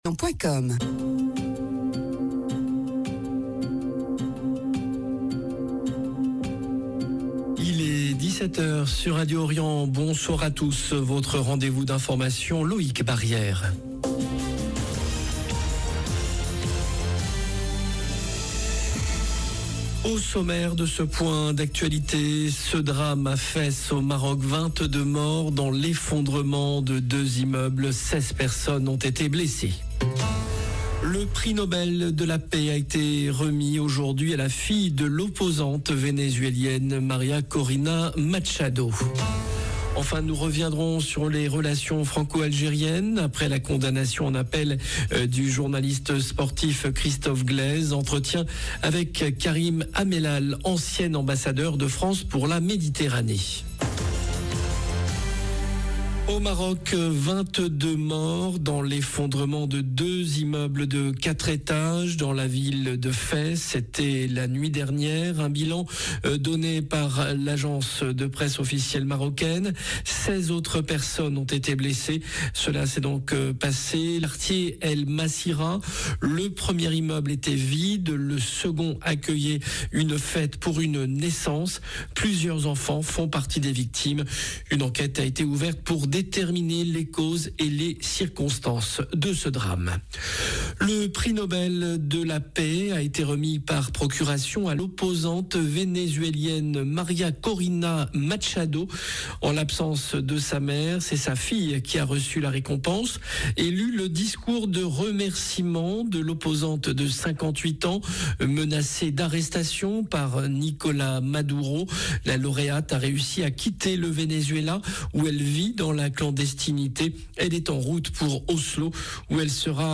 JOURNAL DE 17H
Entretien avec Karim Amellal, ancien ambassadeur de France pour la Méditerranée 0:00 9 min 3 sec